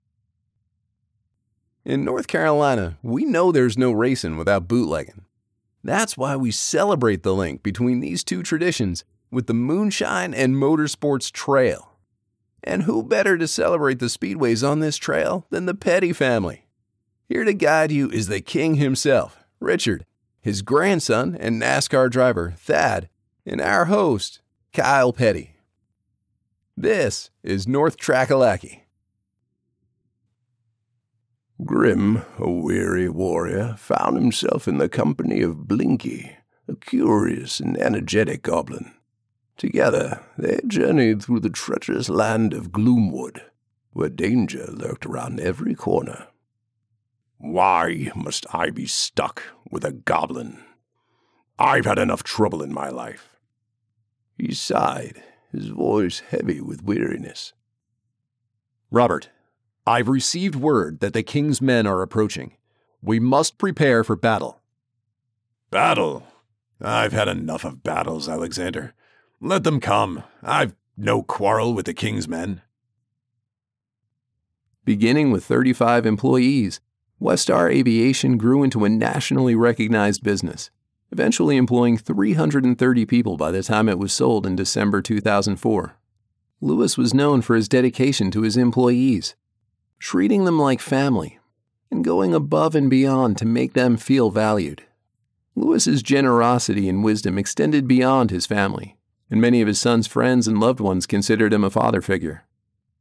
Articulate, intelligent, conversational. Your favorite, laid-back college professor.
US Midatlantic, US Southeast, US Northeast
Middle Aged